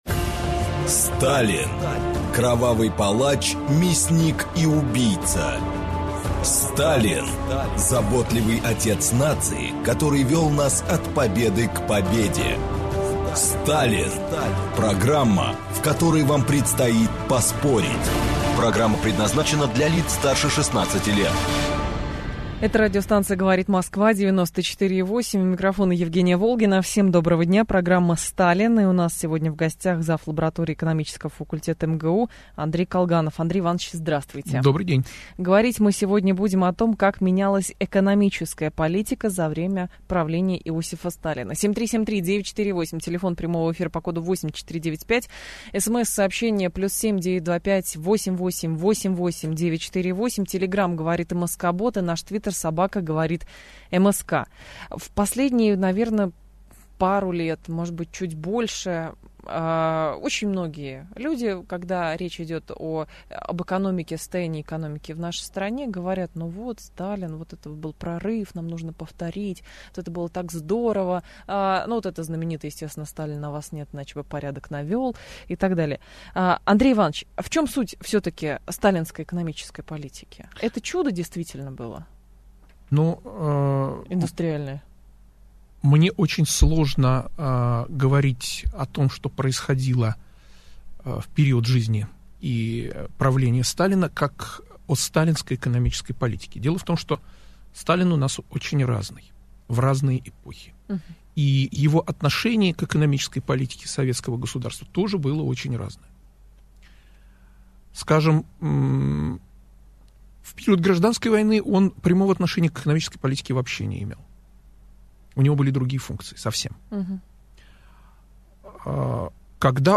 Аудиокнига Как менялась экономическая политика за время правления Сталина | Библиотека аудиокниг